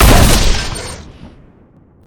shoot3.ogg